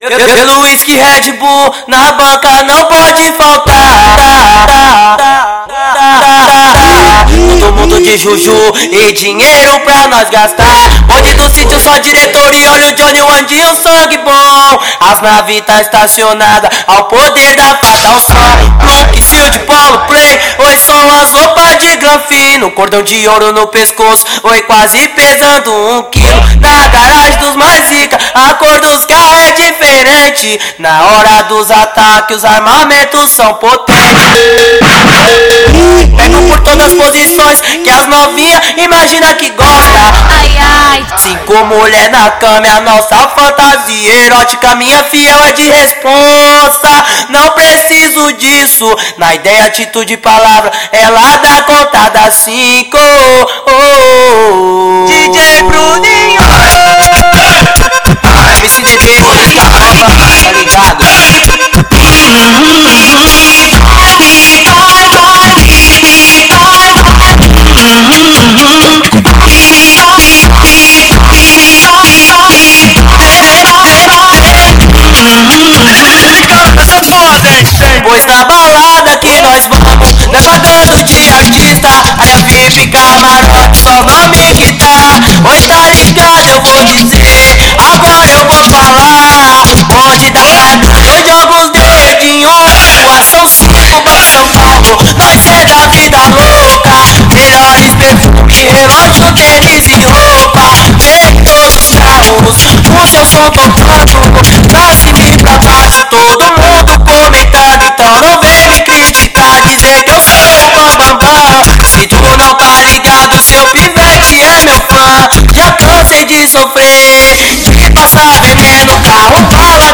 funk.